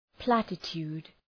Προφορά
{‘plætə,tu:d}